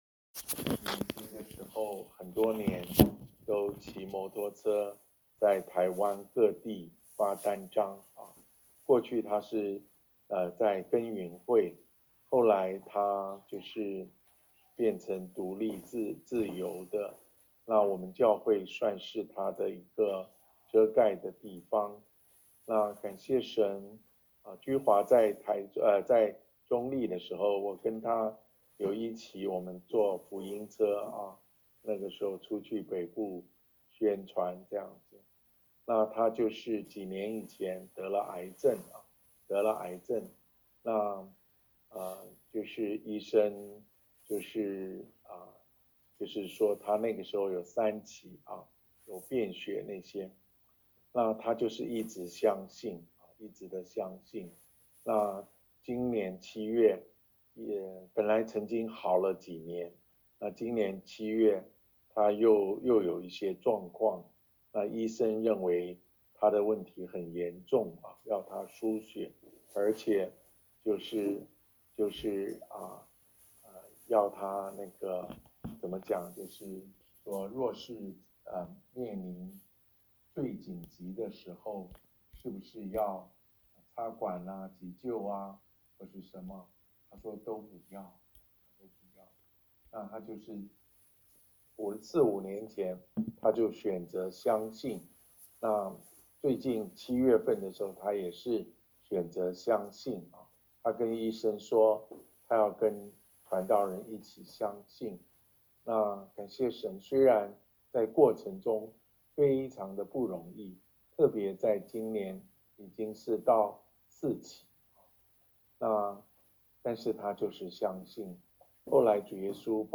講道下載
497 主日信息： 主日信影音檔(1- 40分） 聲音檔： Download